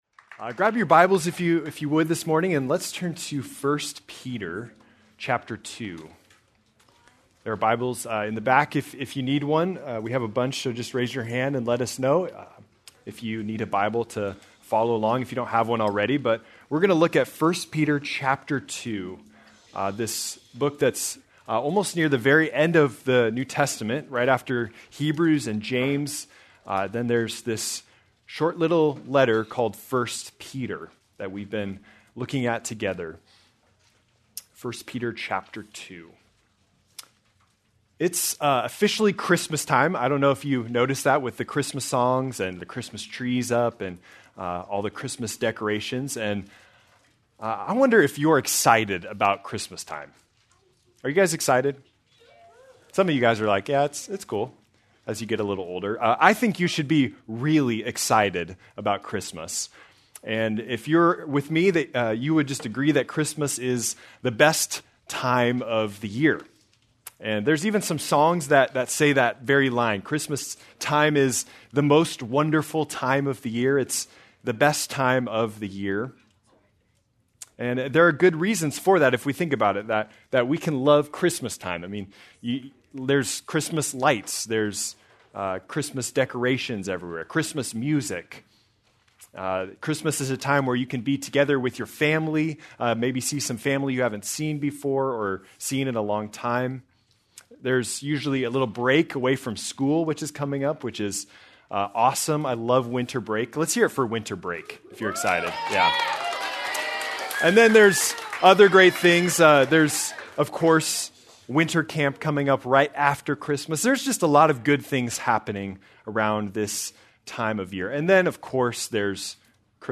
December 14, 2025 - Sermon